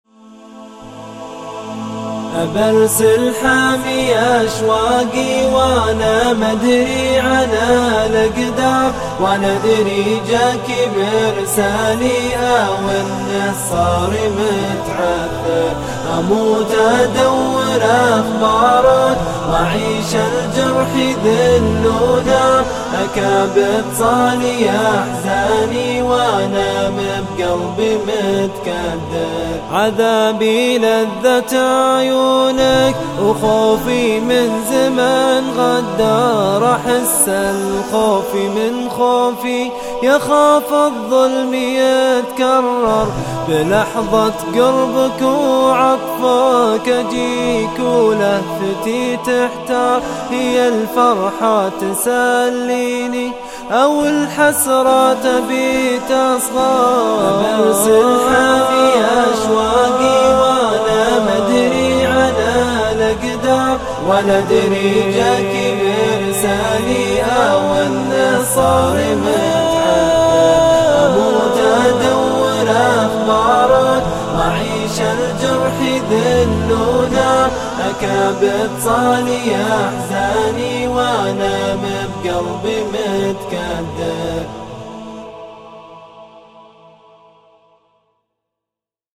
نقدم إليكم اليوم نشيد
مهرجان العثيم مول
إنشاد وآداء أكثر من راائع